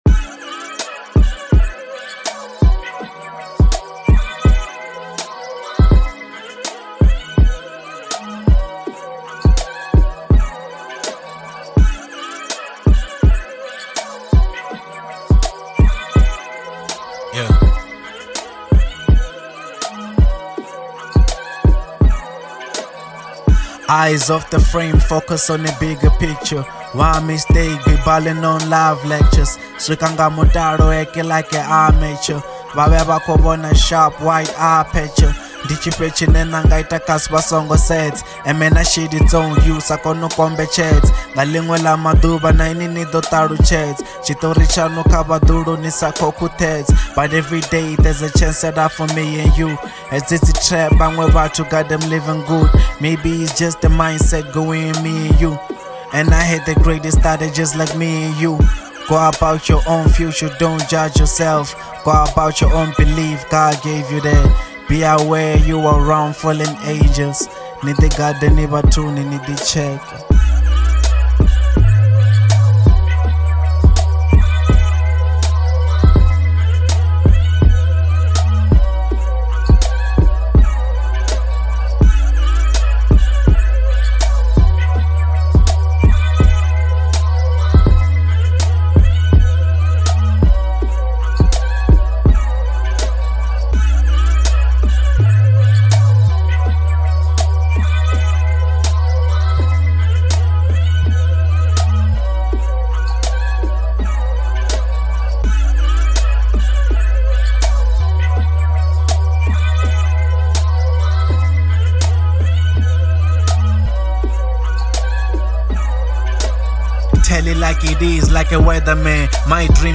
03:45 Genre : Venrap Size